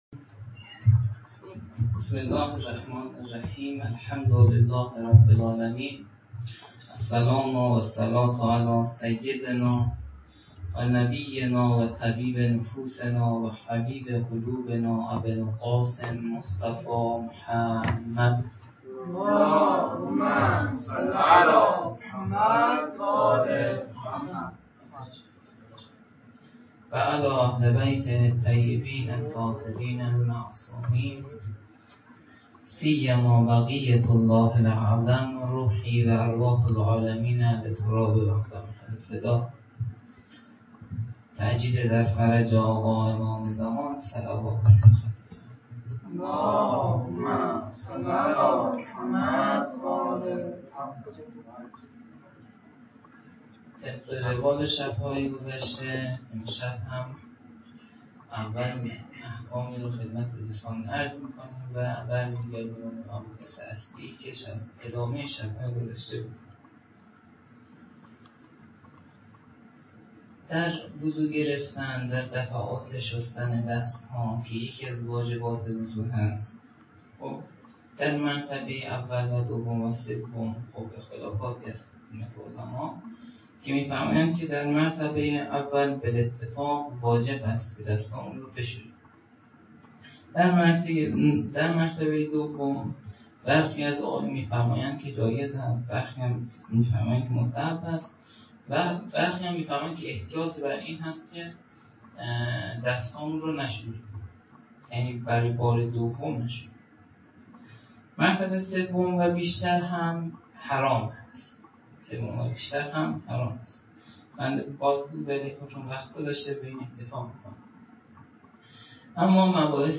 sokhanrani.mp3